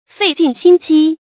fèi jìn xīn jī
费尽心机发音